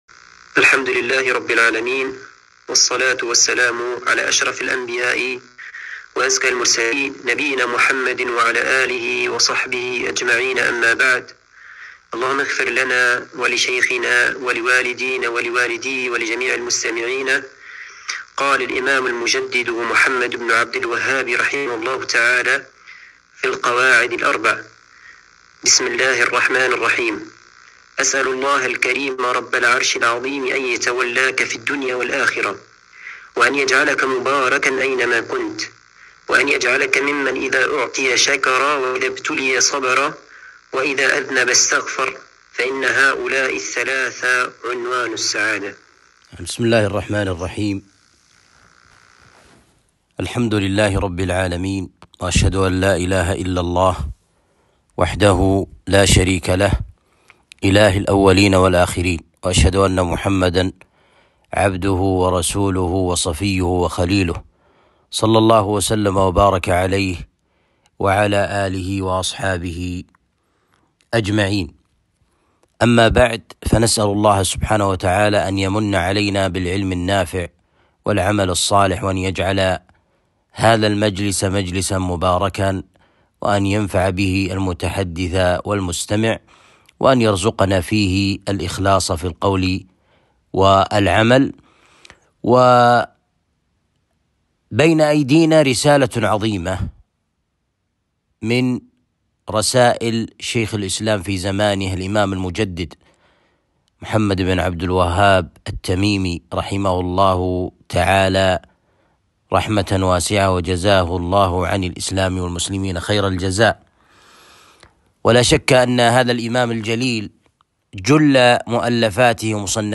القواعد الأربع الدرس الأول